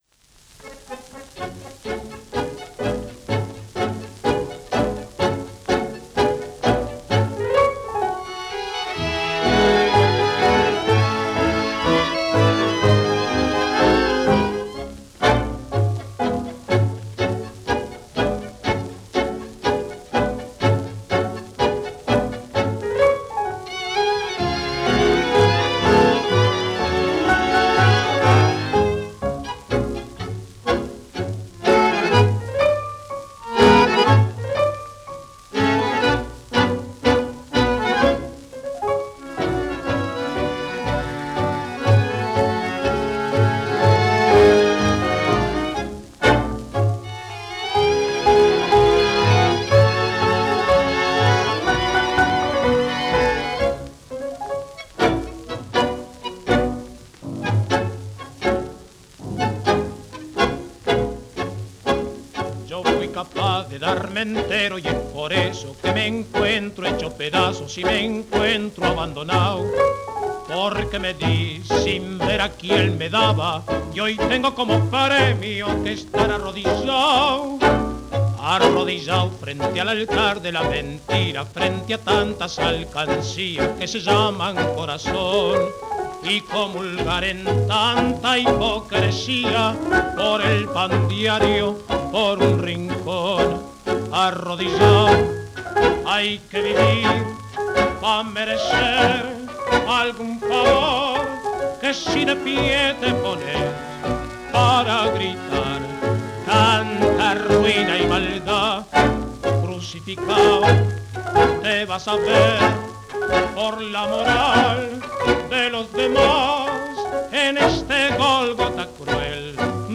Off-Beat
Mostly Rhythmical Tangos